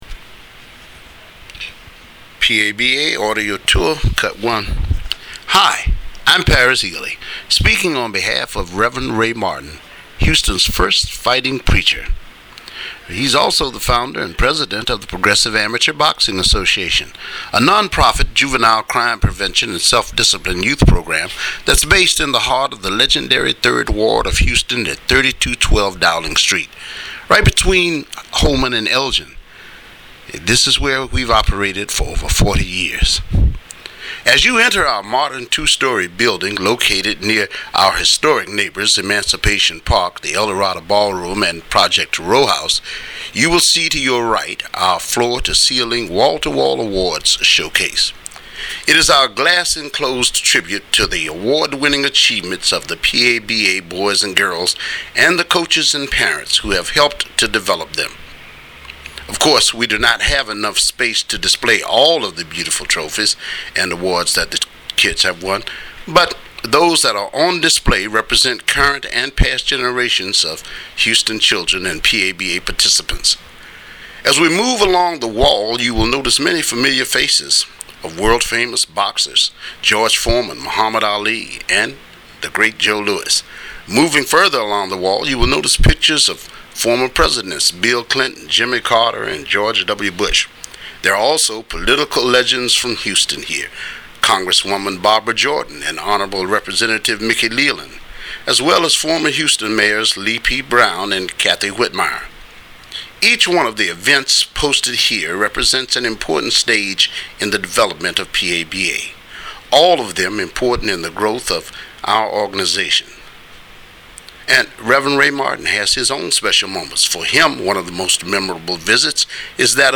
audio walking tours